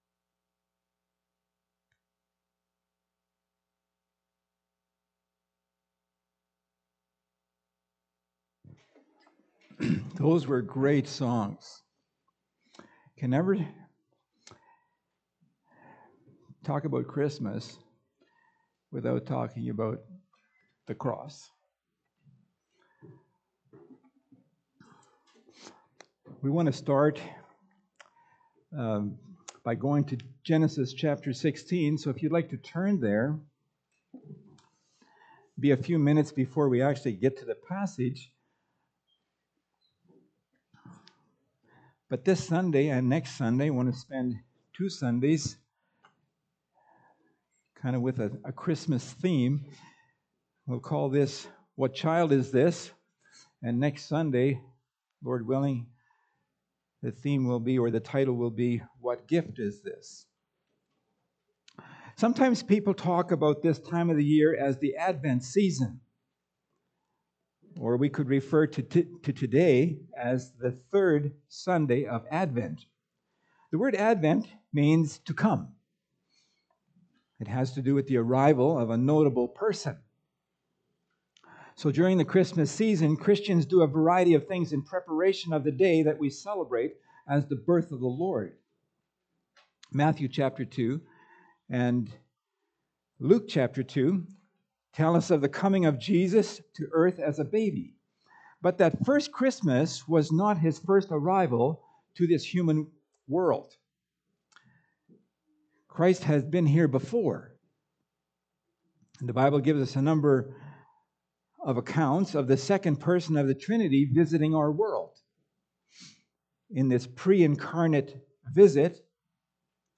Pulpit Sermons Key Passage